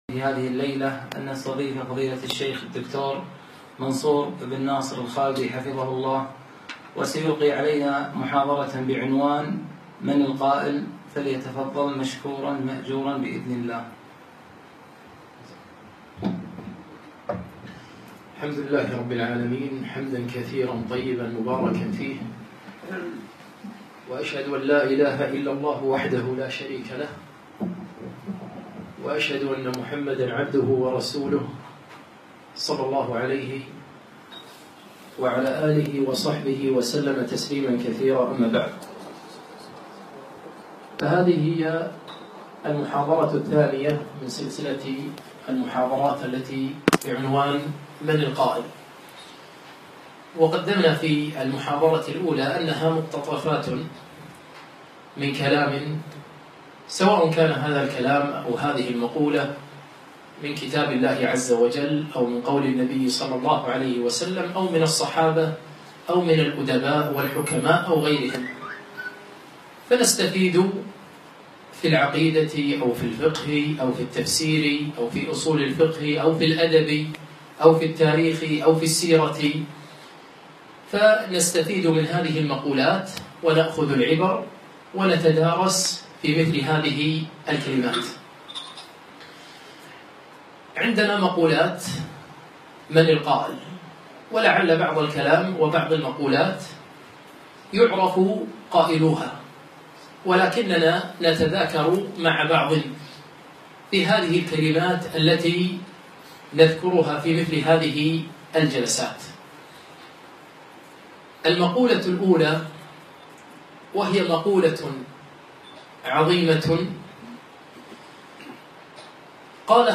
2- من القائل ؟ - المحاضرة الثانية